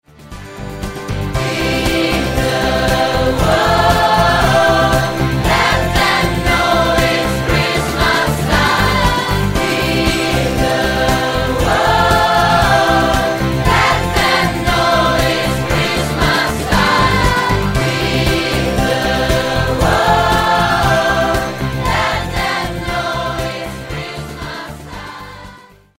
Microfono registrazione voci AKG C 414 XLII